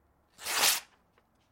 描述：撕纸